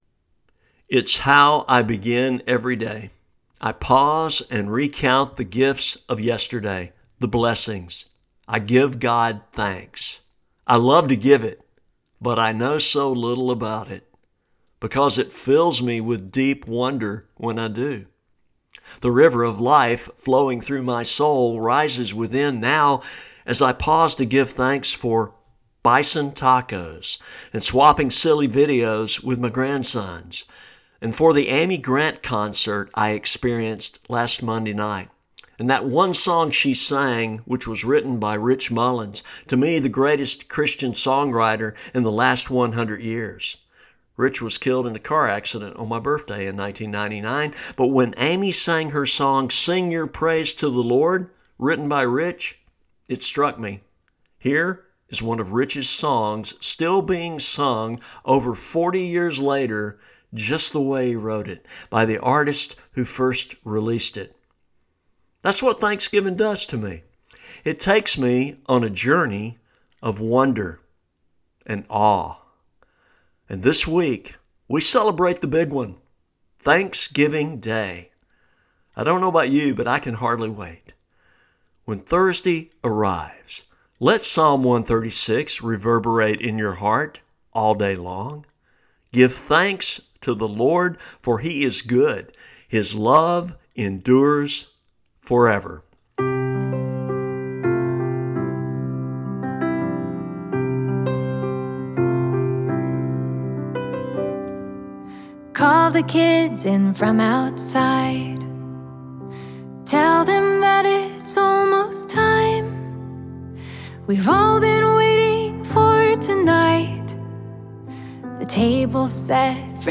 Four good friends accepted my invitation to gather at the Table this week to share their Thanksgiving for 2024.